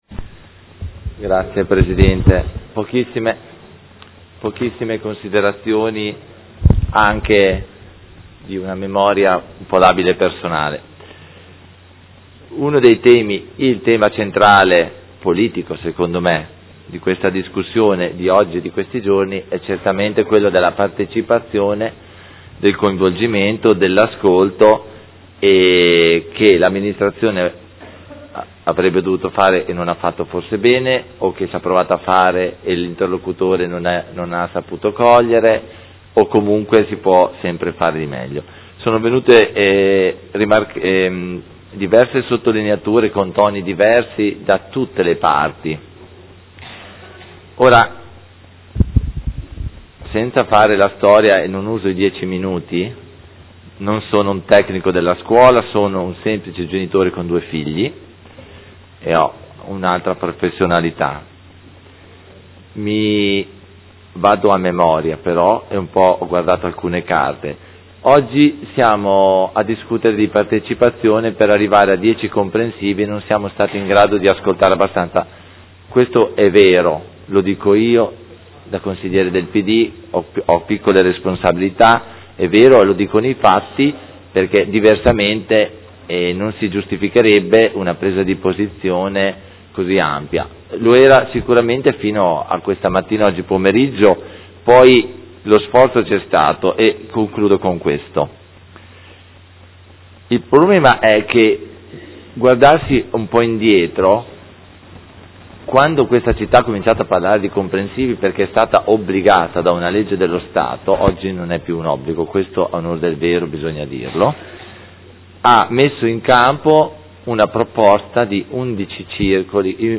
Antonio Carpentieri — Sito Audio Consiglio Comunale
Seduta del 26/11/2015 Dibattito. Delibera: Riorganizzazione della rete scolastica e costituzione degli Istituti Comprensivi